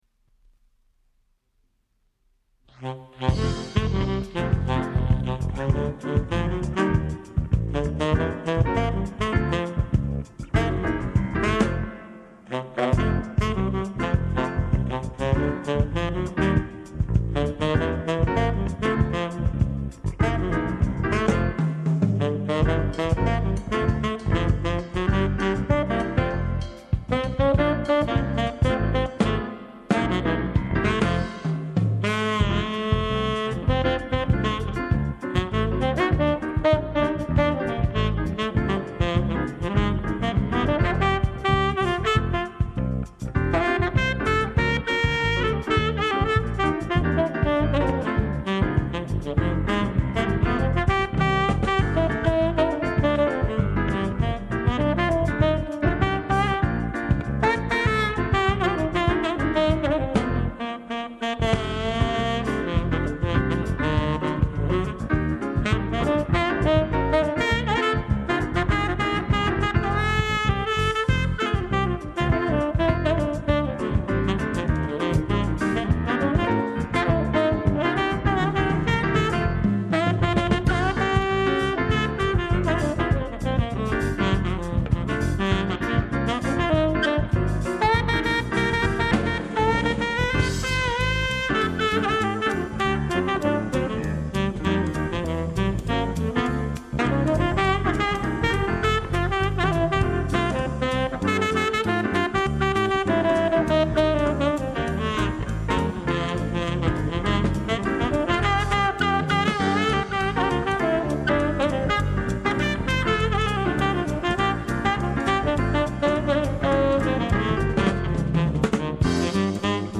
modern jazz